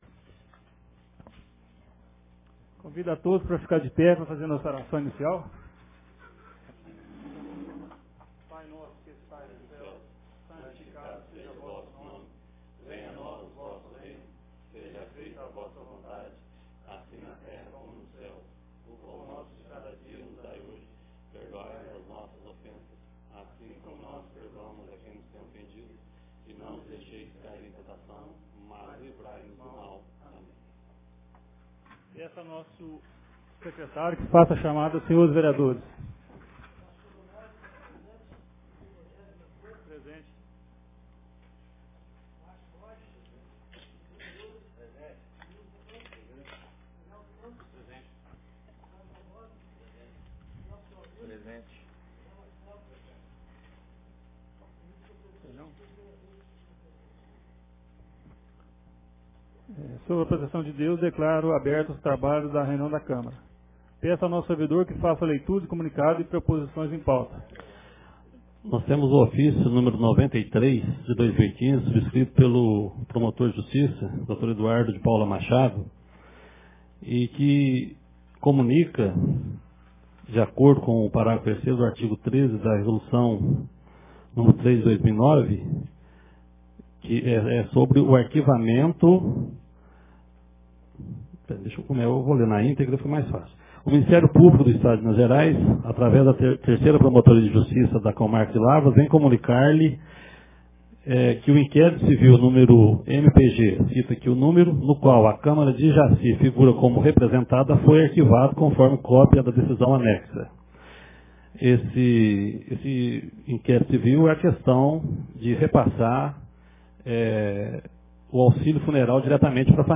Ata da 4ª Reunião Ordinária de 2015